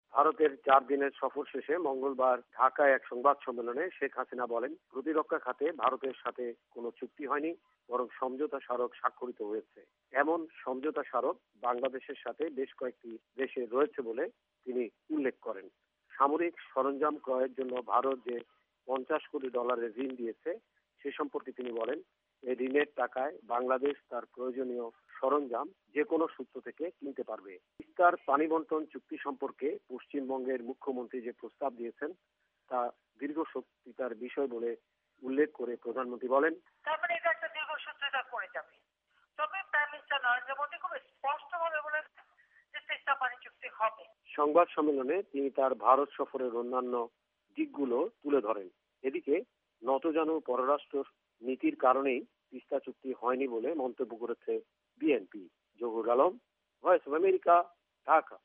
ভারতে ৪ দিনের সফর শেষে মঙ্গলবার ঢাকায় এক সংবাদ সম্মেলনে শেখ হাসিনা বলেন প্রতিরক্ষা খাতে ভারতের সাথে কোন চুক্তি হয়নি বরং সমঝোতা স্মারক স্বাক্ষরিত হয়েছে।